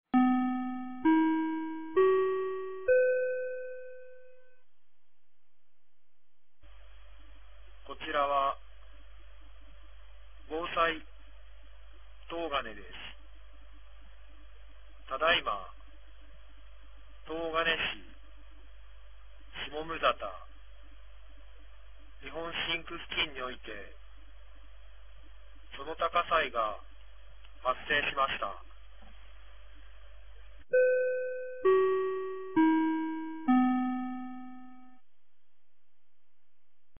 2024年12月29日 14時29分に、東金市より防災行政無線の放送を行いました。